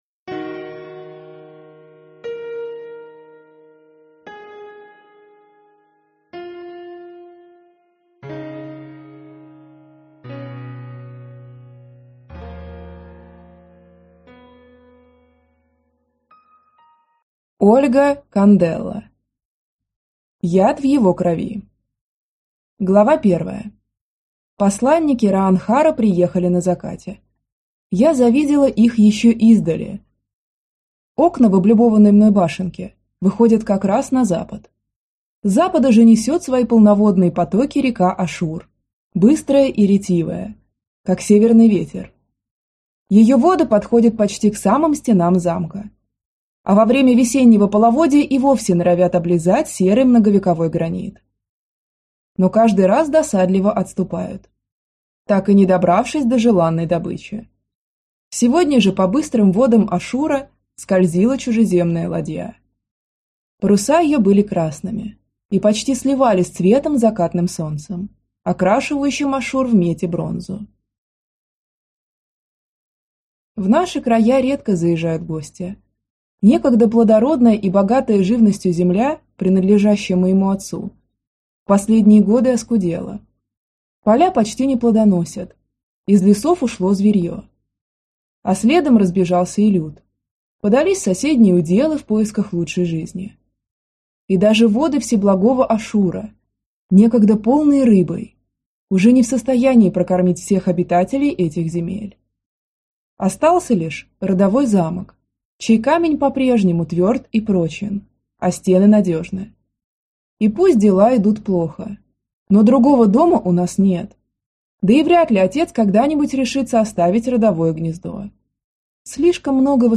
Аудиокнига Яд в его крови - купить, скачать и слушать онлайн | КнигоПоиск